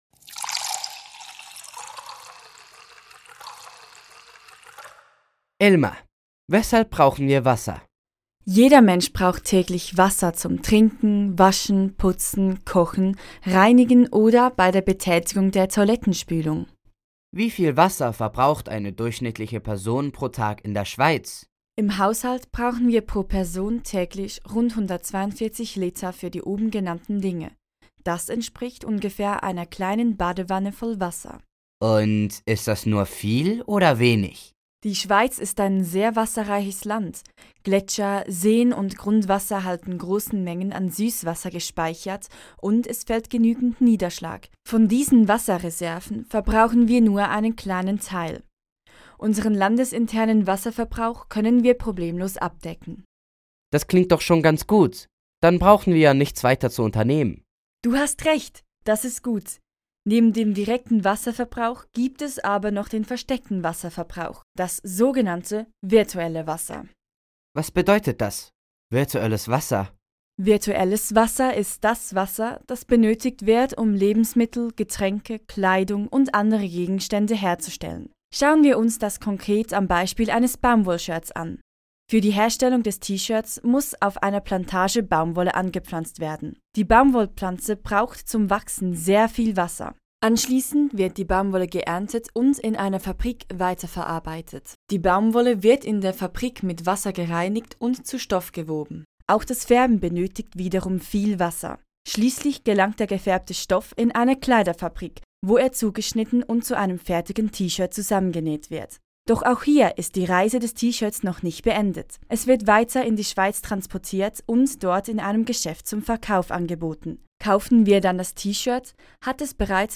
E5 Interview virtuelles Wasser
z2_e5_interview-virtuelles-wasser_klein.mp3